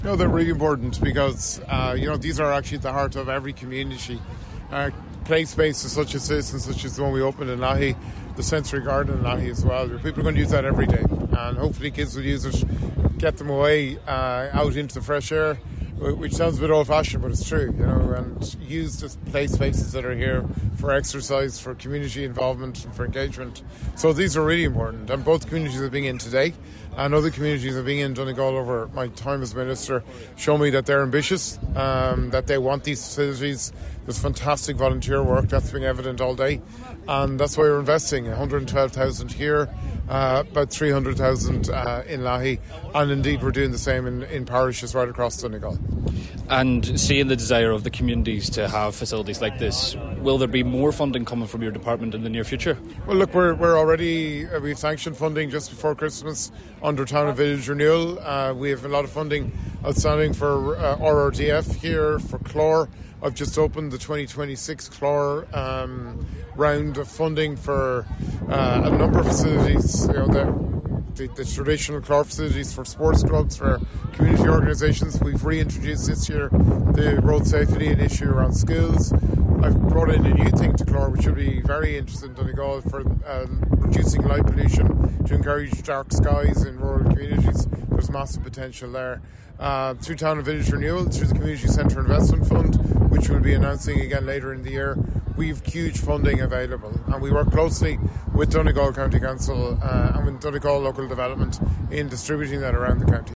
During his speech opening the Castlefinn Playground, Minister Calleary paid tribute to the community volunteers in Castlefinn for their hard work.